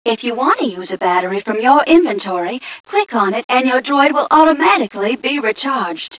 1 channel
mission_voice_ghca011.wav